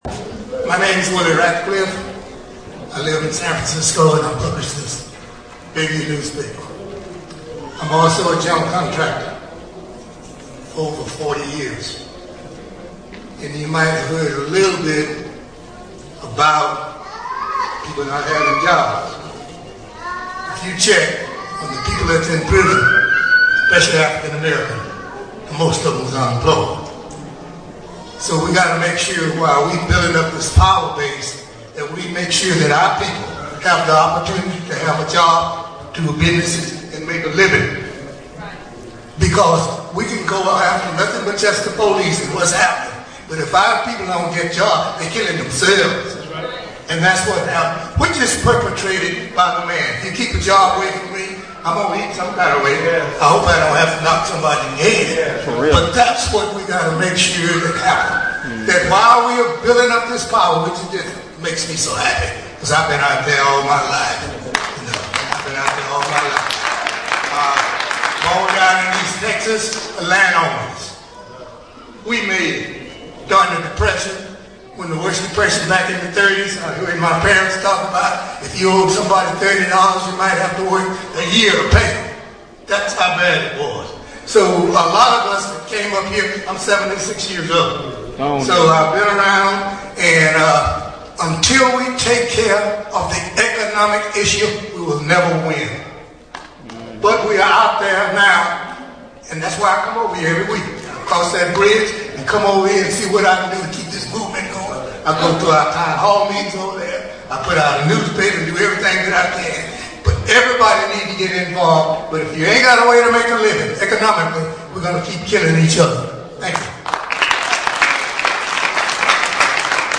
Town Hall Meetings
Olivet Institutional Missionary Baptist Church